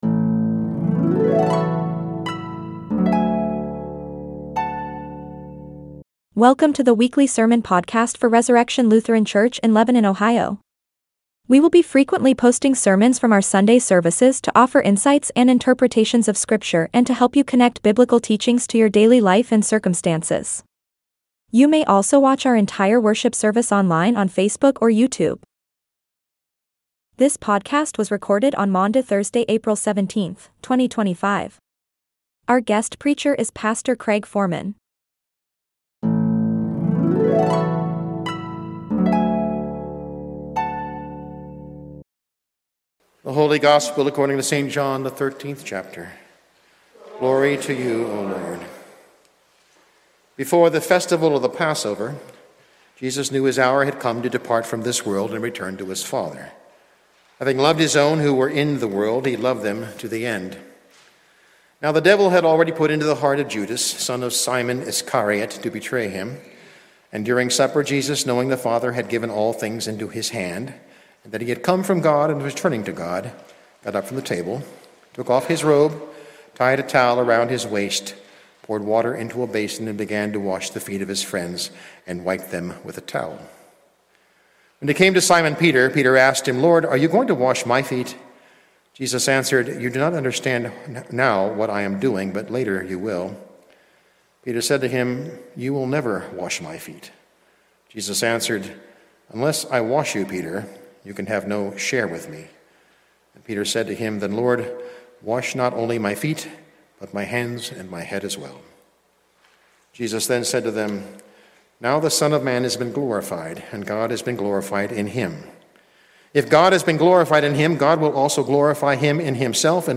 Sermons | Resurrection Lutheran Church